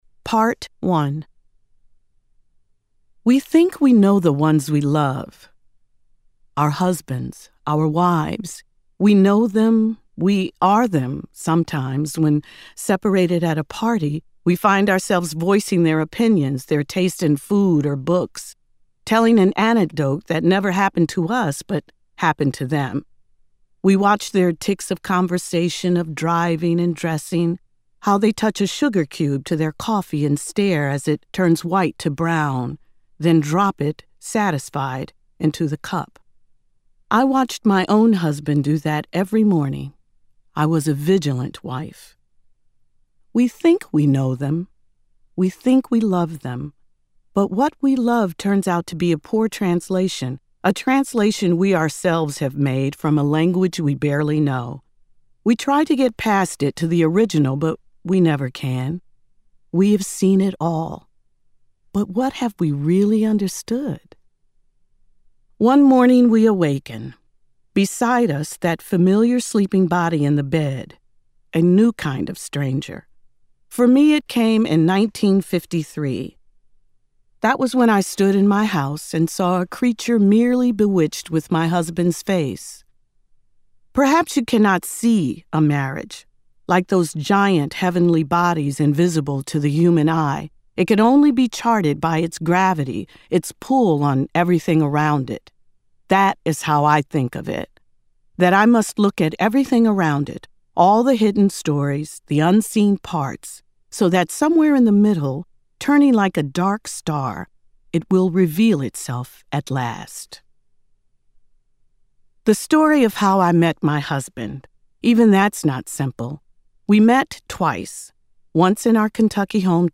S. Epatha Merkerson reads an excerpt from Andrew Sean Greer's third novel, The Story of a Marriage, published in May by Farrar, Straus and Giroux.